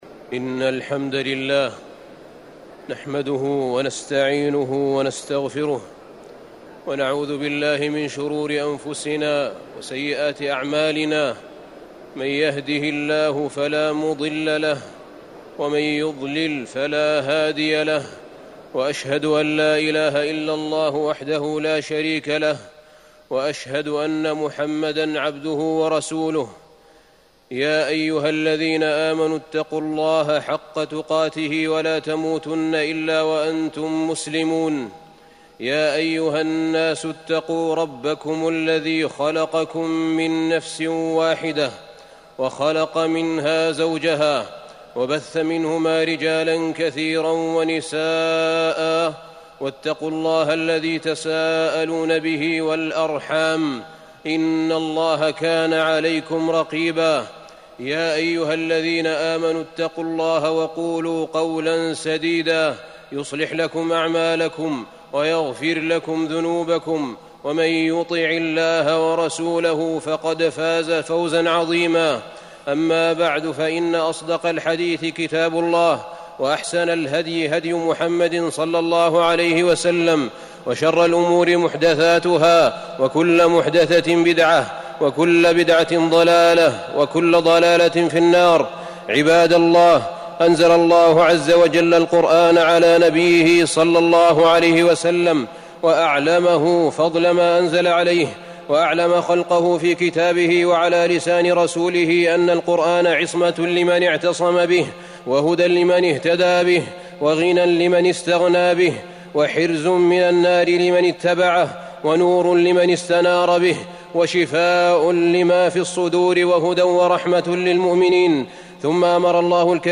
تاريخ النشر ٢٦ صفر ١٤٤١ المكان: المسجد النبوي الشيخ: فضيلة الشيخ أحمد بن طالب حميد فضيلة الشيخ أحمد بن طالب حميد أهل القرآن